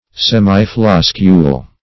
Search Result for " semifloscule" : The Collaborative International Dictionary of English v.0.48: Semifloscule \Sem"i*flos`cule\, n. (Bot.)
semifloscule.mp3